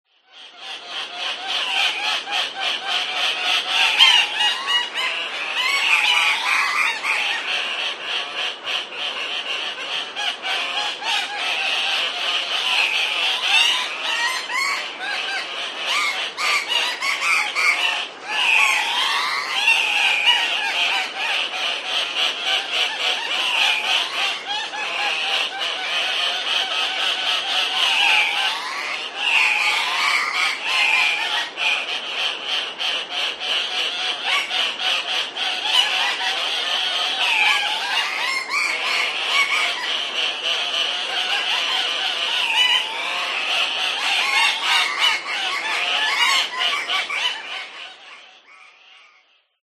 Erkekler ördek sesine benzer bir çağrı ile dişileri cezbetmeye çalışır.
Kaya horozunun sesi.
kaya-horozu-sesi-4.mp3